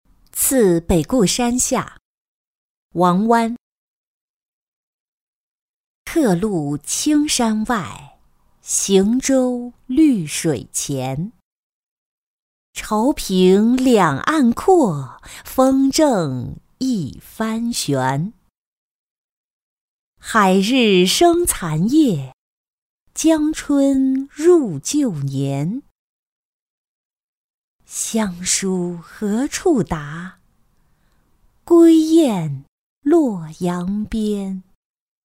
次北固山下-音频朗读